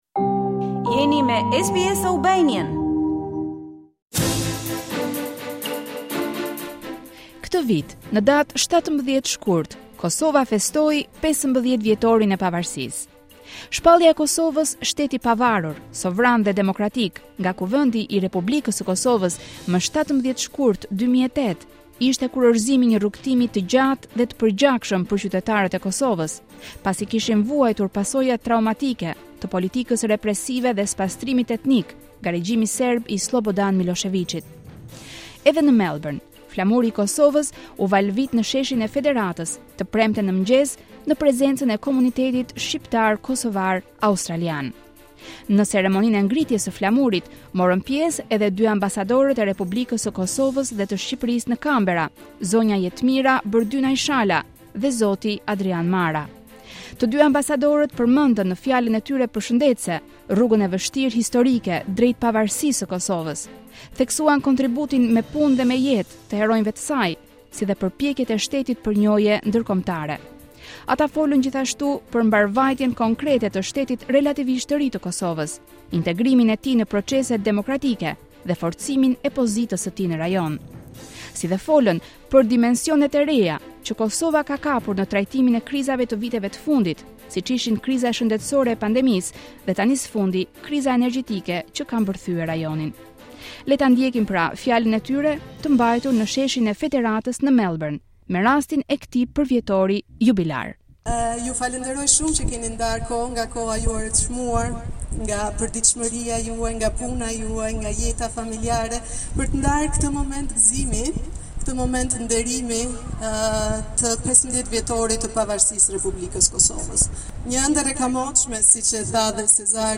Këtë vit në datë 17 shkurt Kosova festoi 15 vjetorin e pavarësisë. Në Melbourne, flamuri i Kosovës u valëvit në sheshin e Federatës, mëngjesin e së premtes, në prezencën e komunitetit Shqiptar dhe Kosovar Australian. Në ceremoninë e ngritjes së flamurit, morën pjesë ambasadorja e Republikës së Kosovës, zonja Jetmira Bërdynaj Shala dhe përfaqësuesi diplomatik i Shqipërisë në Canberra, zoti Adrian Mara.